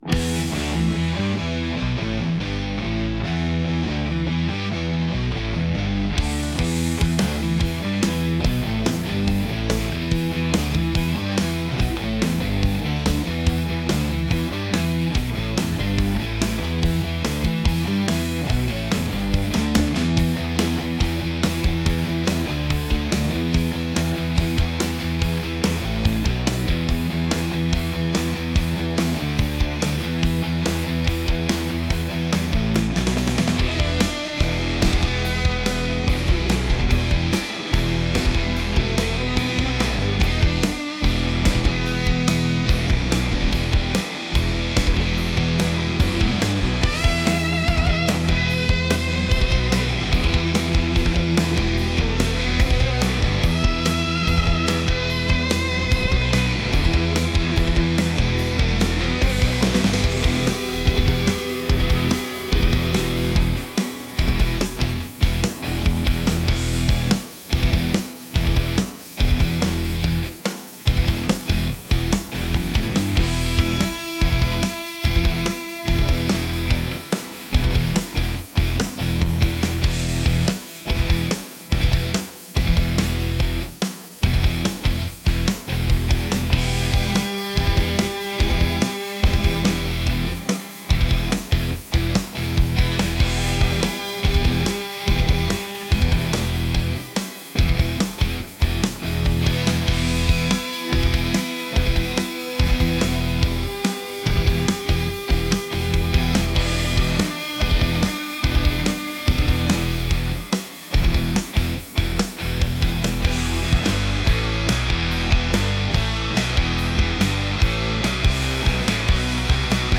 energetic | rock